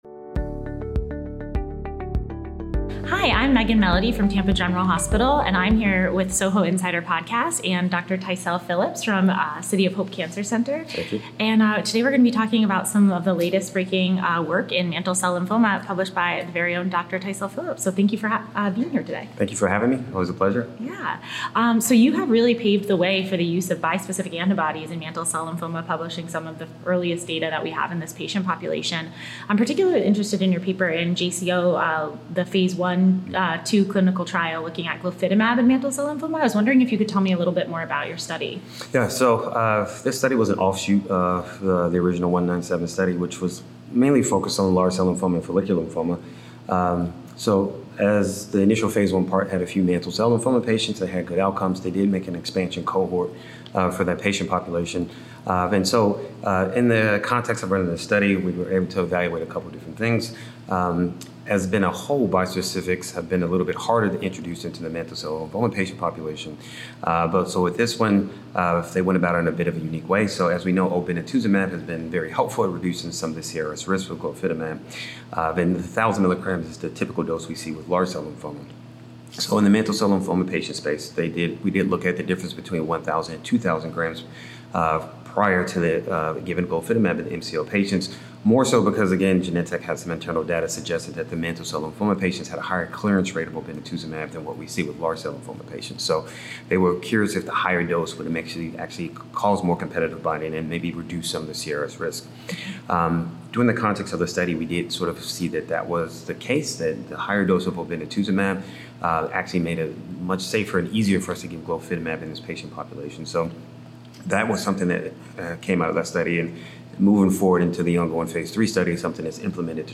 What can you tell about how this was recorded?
In this podcast episode recorded at the Thirteenth Annual Meeting of the Society of Hematologic Oncology (SOHO 2025)